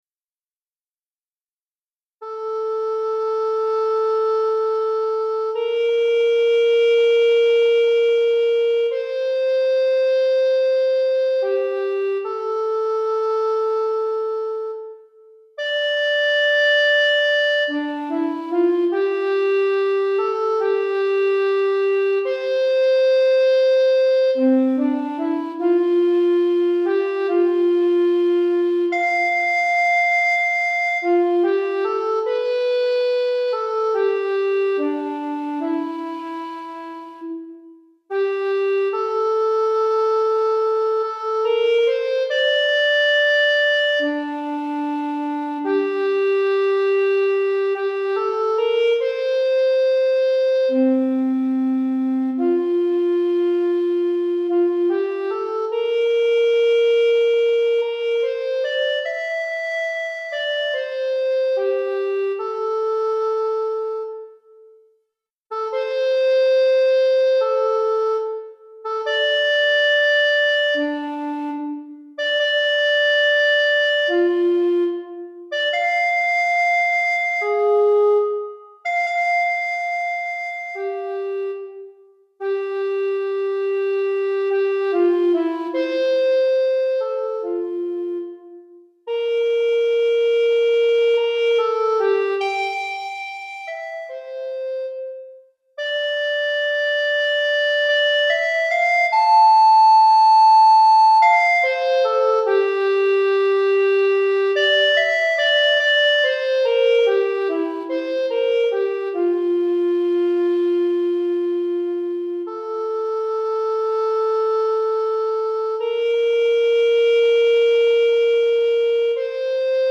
Flûte à Bec Solo